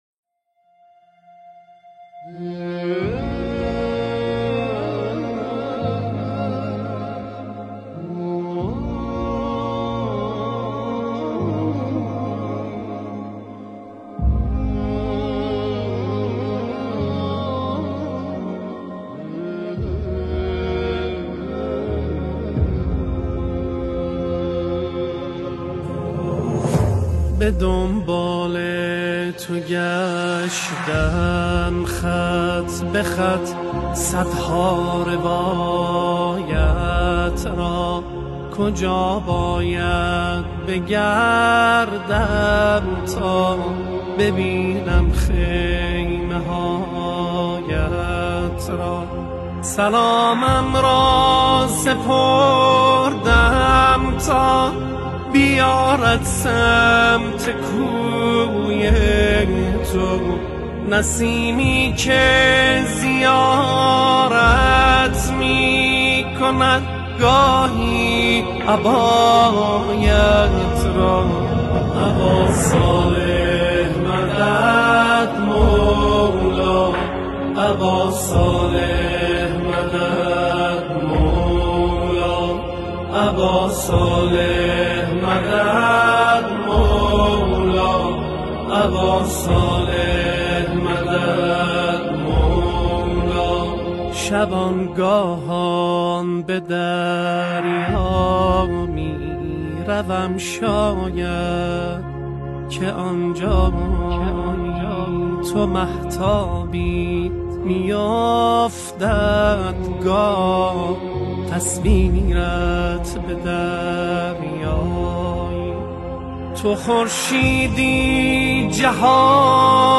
مناجات مهدوی
با نوای دلنشین